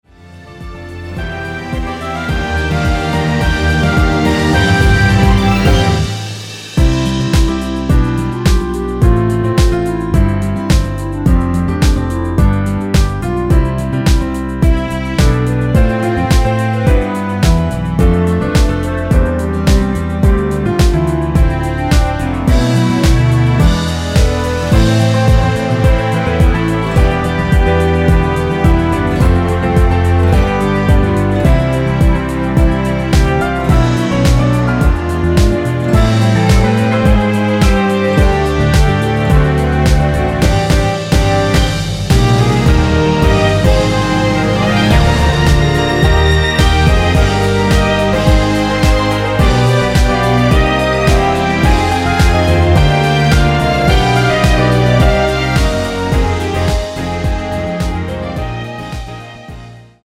원키에서(-1)내린 멜로디 포함된 MR입니다.
앞부분30초, 뒷부분30초씩 편집해서 올려 드리고 있습니다.